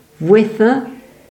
Vuissens (French: [vɥisɑ̃s]; Arpitan: Vouéssens [vwɛˈsɛ̃]